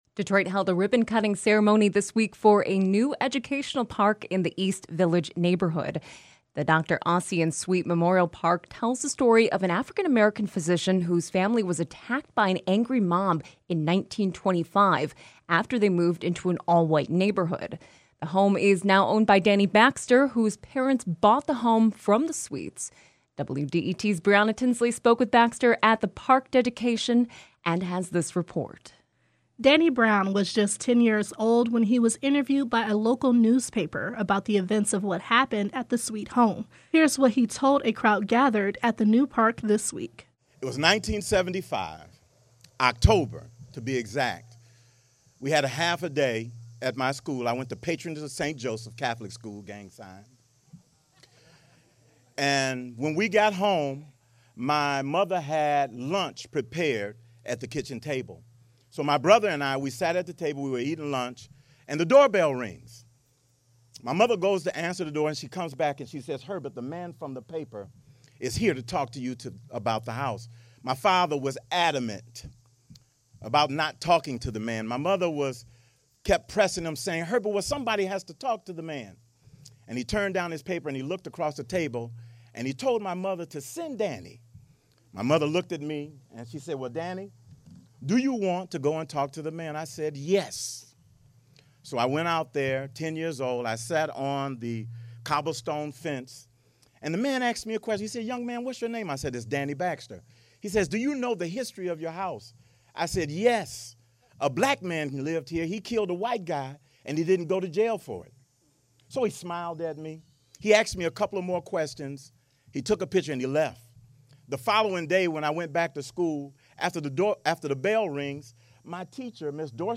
The following interview has been edited for clarity and length